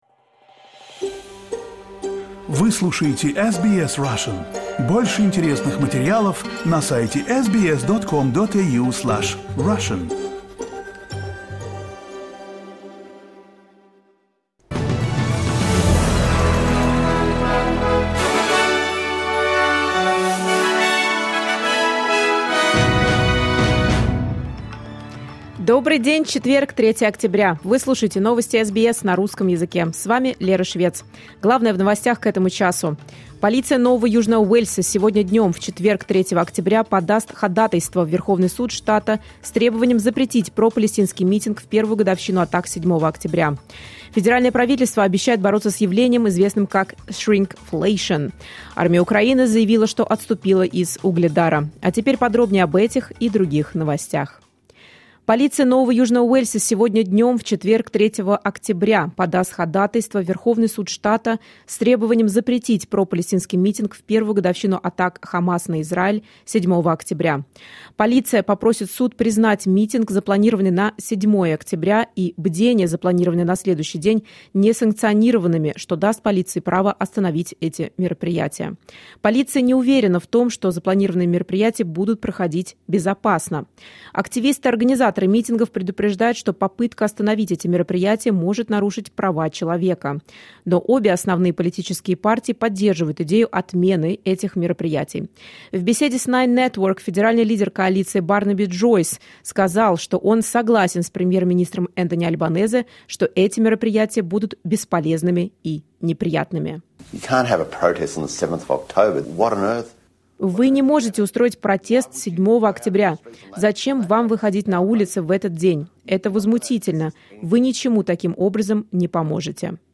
Новости SBS на русском языке — 03.10.2024